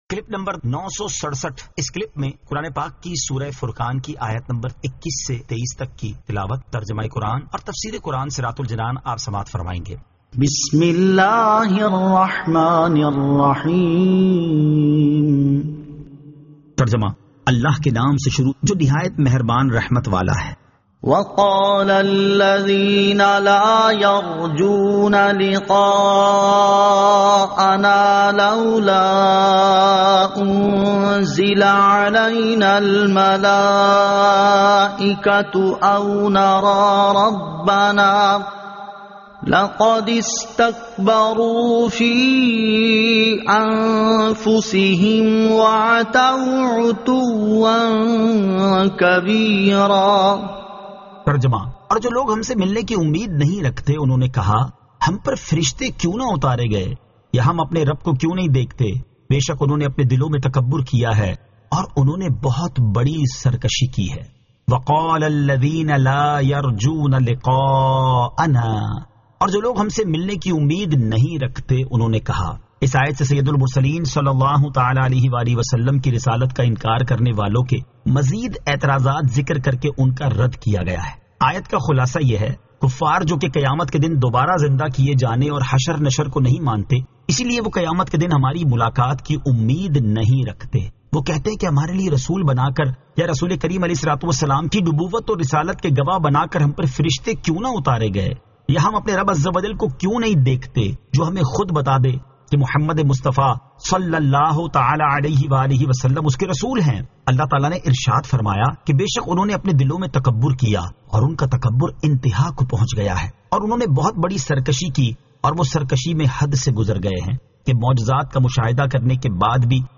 Surah Al-Furqan 21 To 23 Tilawat , Tarjama , Tafseer